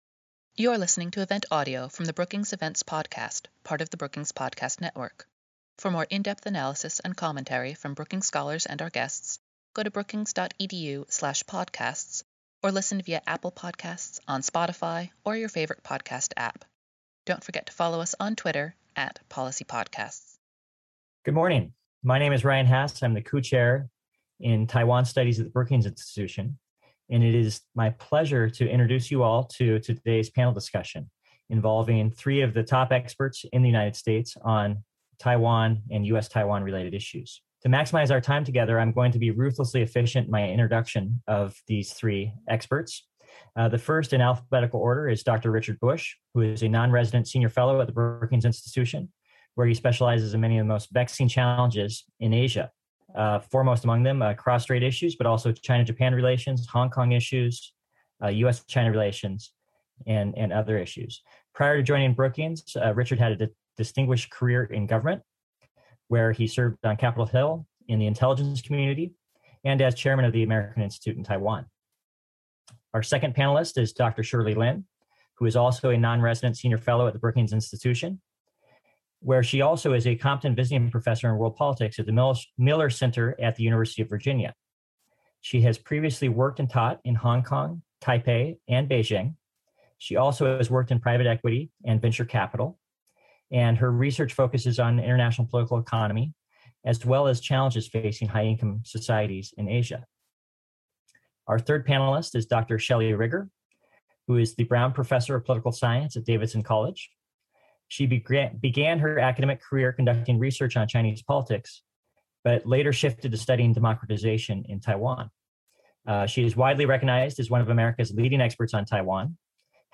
On October 12, the Center for East Asia Policy Studies at the Brookings Institution hosted a group of experts to examine how politics is impacting policy in Washington and Taipei and what the implications are for U.S.-Taiwan relations.